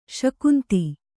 ♪ śakunti